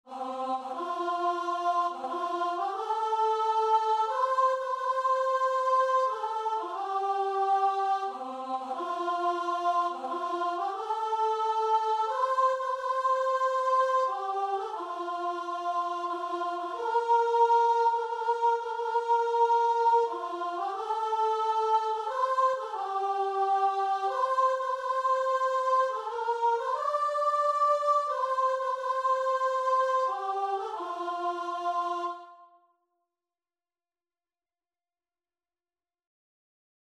ANdante =90
3/4 (View more 3/4 Music)
Classical (View more Classical Guitar and Vocal Music)